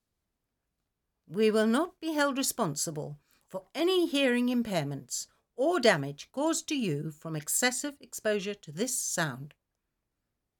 Micro_Percussion
mgreel micro-percussion morphagene percussion plonk sound effect free sound royalty free Memes